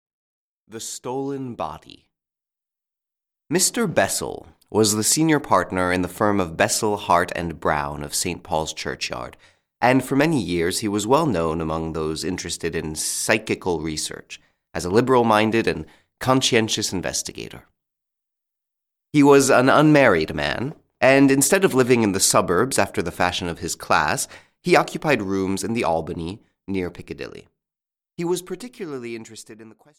Audiobook The Stolen Body by H. G. Wells.
Ukázka z knihy